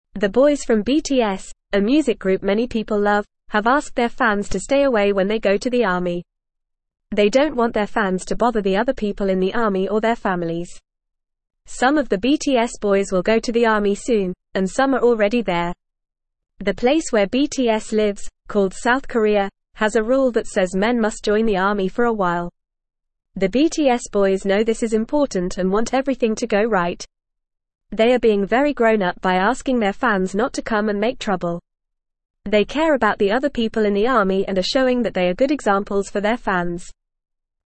Fast
English-Newsroom-Beginner-FAST-Reading-BTS-Boys-Ask-Fans-to-Stay-Away-from-Army.mp3